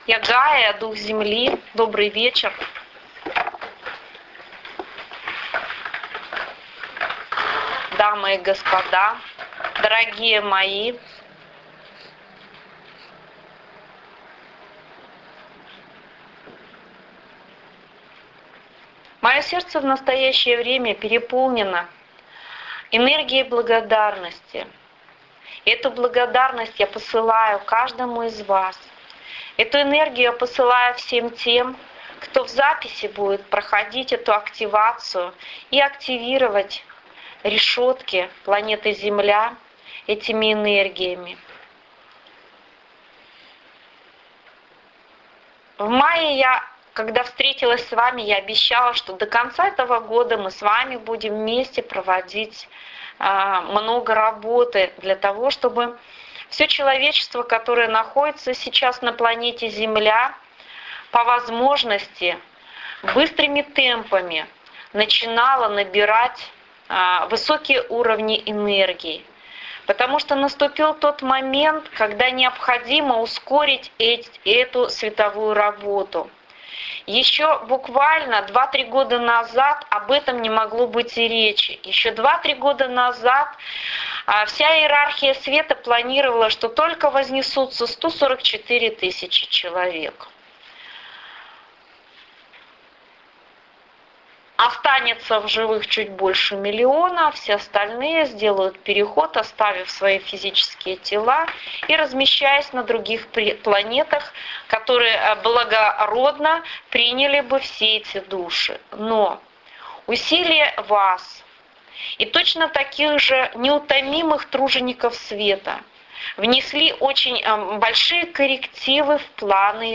Рэйки - Возрождение - Живой ченнелинг Гайи
Предлагаю вам ченнелинг Леди Гайи, который она траслировала после того, как мы закончили медитацию Активации Энергий Стихий в Кристалле Равновесия.